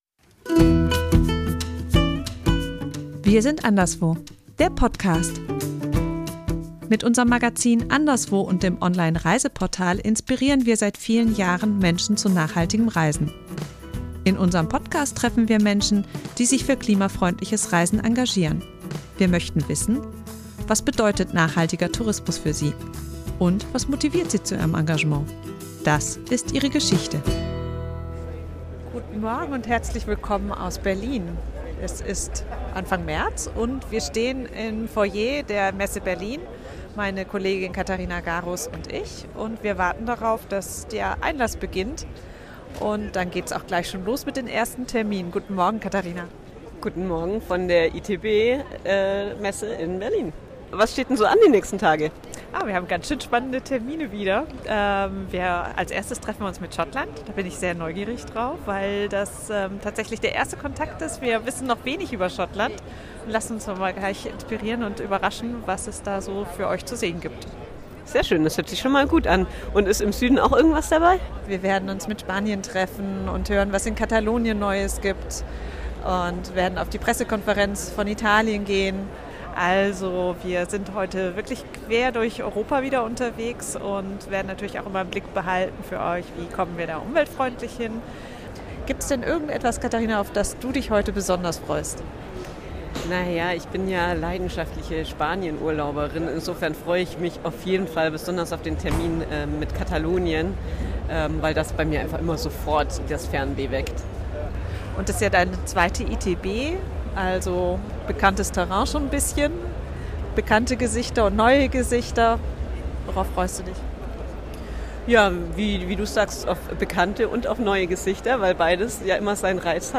Für die Anderswo-Redaktion ist die Tourismusmesse ITB in Berlin jedes Jahr ein wichtiger Impulsgeber. Wir schlendern mit Euch über die Messe und halten Ausschau nach spannenden Reisetipps in Europa, die sich nachhaltig bereisen lassen.